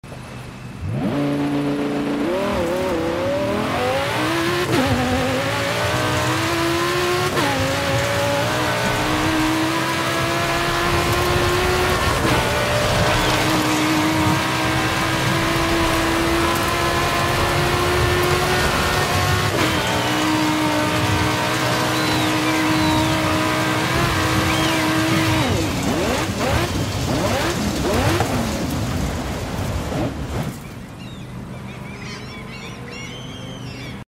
2011 Lamborghini Gallardo LP 570 4 Sound Effects Free Download